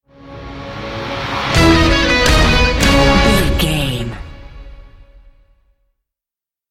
Ionian/Major
C#
horns
drums
electric guitar
synthesiser
orchestral
orchestral hybrid
dubstep
aggressive
energetic
intense
strings
bass
synth effects
wobbles
heroic
driving drum beat
epic